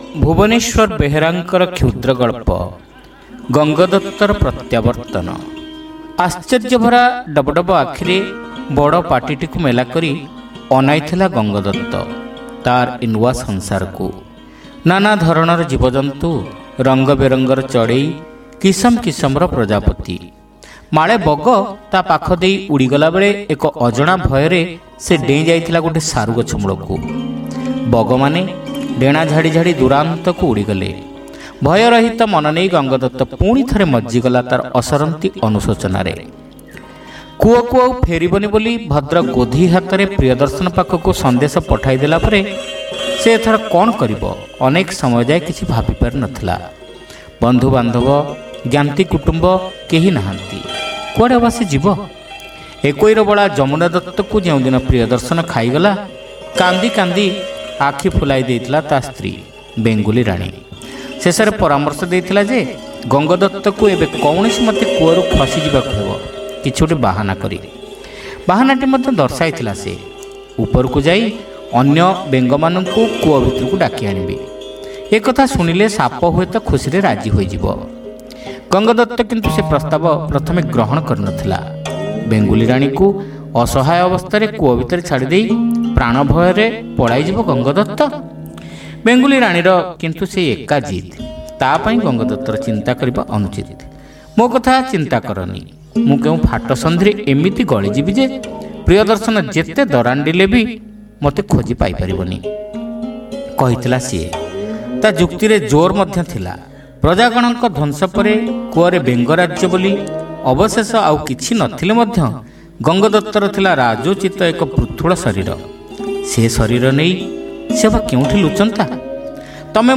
ଶ୍ରାବ୍ୟ ଗଳ୍ପ : ଗଙ୍ଗଦତ୍ତର ପ୍ରତ୍ୟାବର୍ତନ